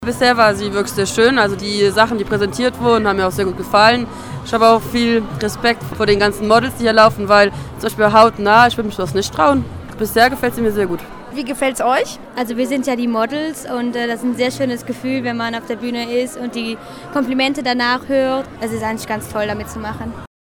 Am Samstag drehte sich im vollbesetzen Mozartsaal in Worriken alles um das Thema „Die aktuellen Modetrends für den Herbst und den Winter“.
Besucher und Veranstalter zeigten sich im Gespräch